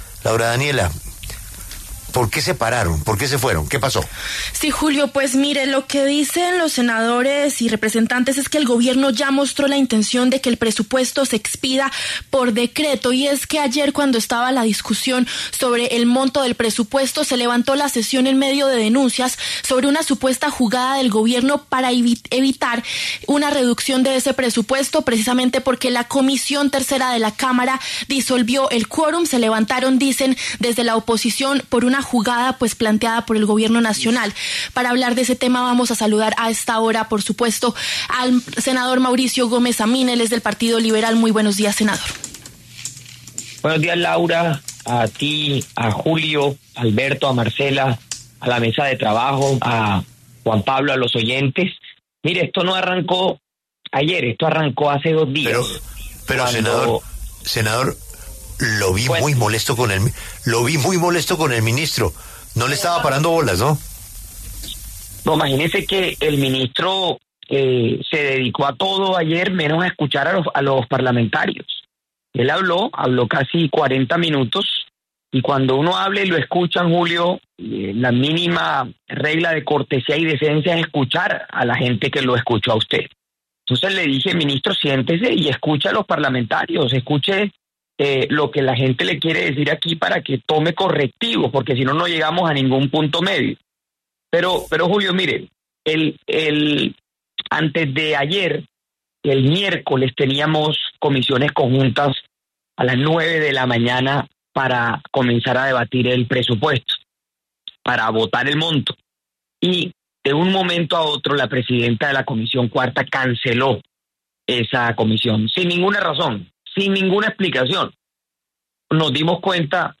Mauricio Gómez Amín, senador liberal, y Jorge Bastidas, representante del Pacto Histórico, debatieron en La W sobre el Presupuesto General de la Nación para el 2026, ¿se mantiene el monto de $557 billones?